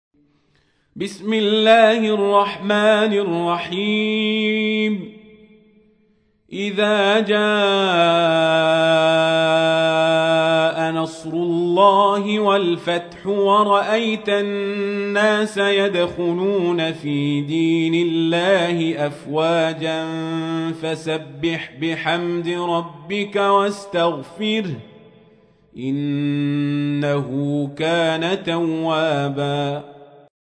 سورة النصر / القارئ القزابري / القرآن الكريم / موقع يا حسين